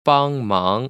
[bāng//máng] 빵망   ▶